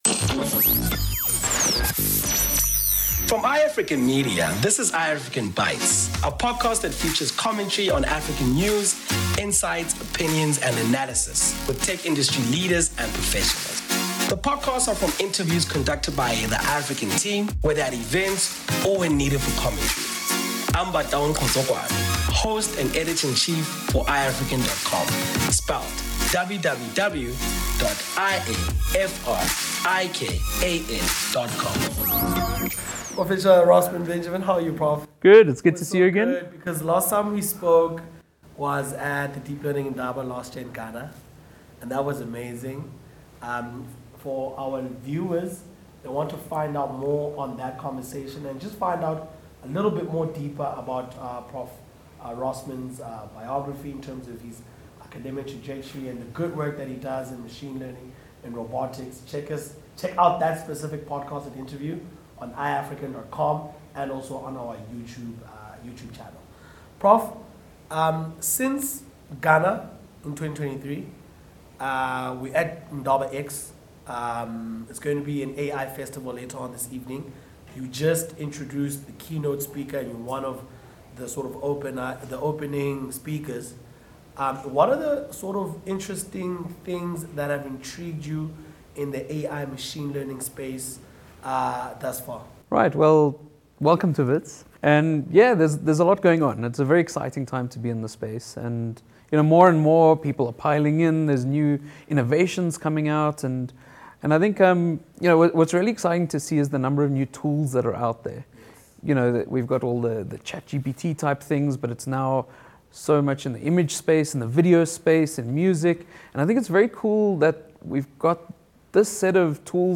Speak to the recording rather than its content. The podcasts are from interviews conducted by iAfrikan.con staff whether at events or when needed for commentary.